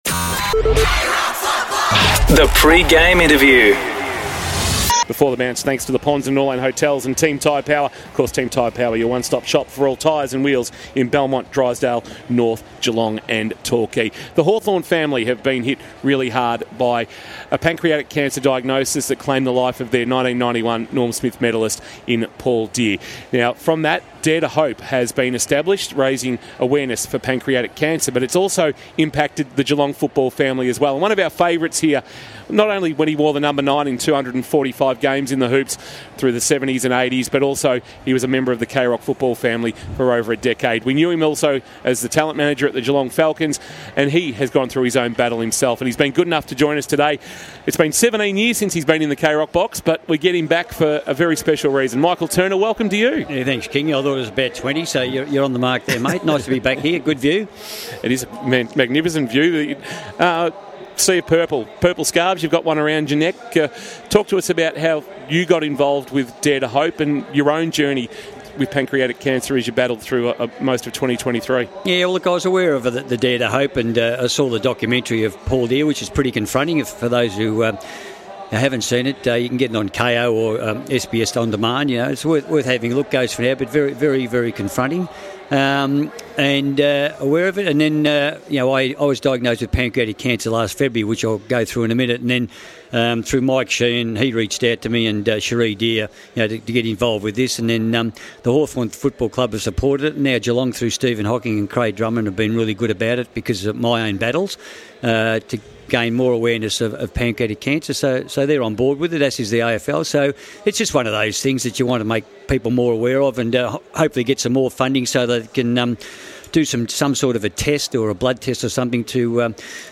2024 - Round 3 - Hawthorn vs. Geelong: Pre-match interview - Michael Turner (Geelong Great)